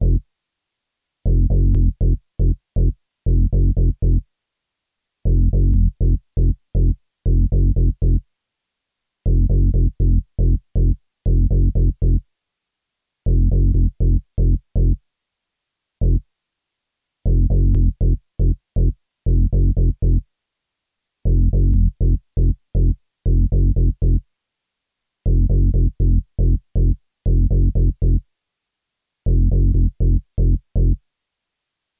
东部退出舞台低音120bpm16bars
描述：用我的Novation Ultranova合成器制作的循环。
这些循环具有亚洲风味，可用于各种电子甚至HipHop类型。
Tag: 120 bpm Electronic Loops Bass Loops 2.69 MB wav Key : Unknown